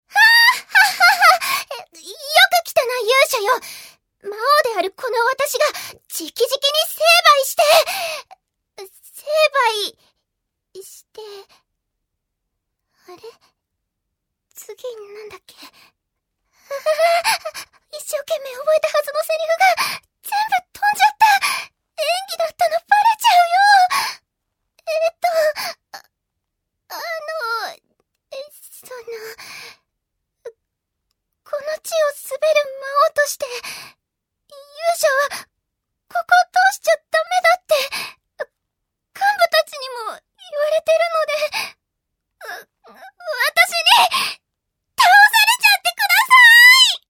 Top Info List Profile Contact 気弱系(おとなしめ) 2020年6月30日 https